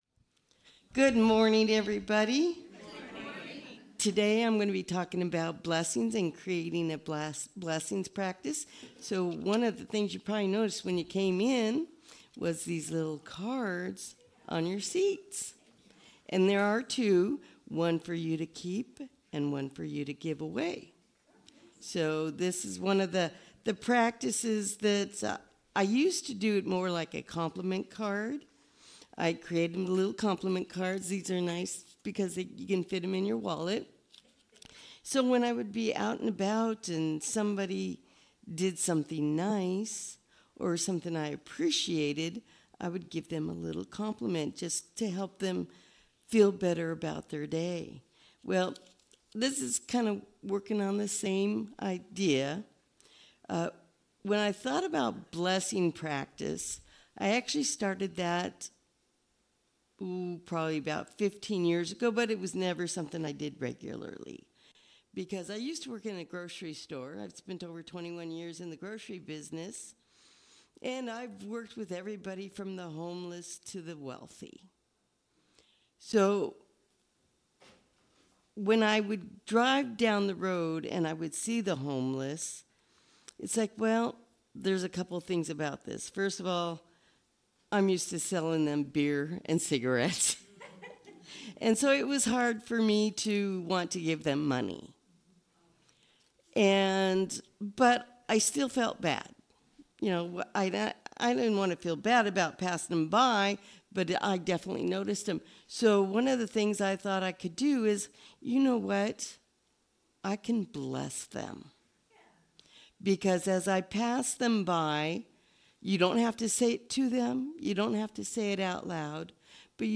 The audio recording (below the video clip) is an abbreviation of the service. It includes the Lesson, Featured Song, and Meditation.